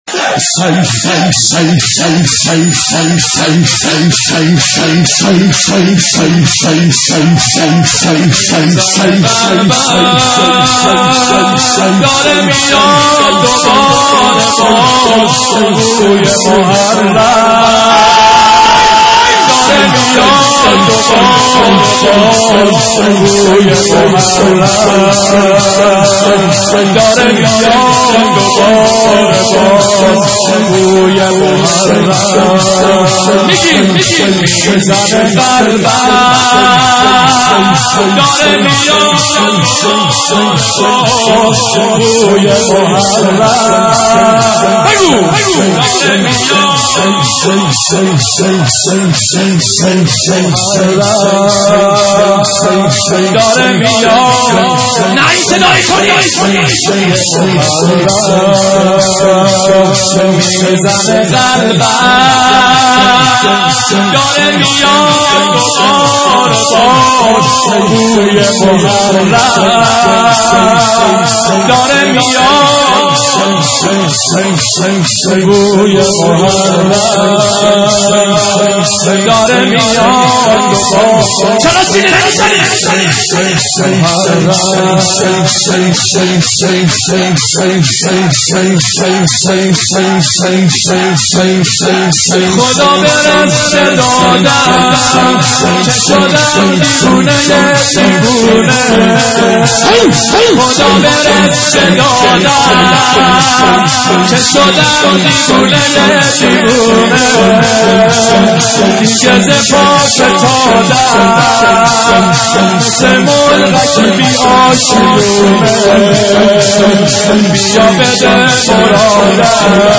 میزنه قلبم،داره میاد دوباره باز بوی محرم(شور)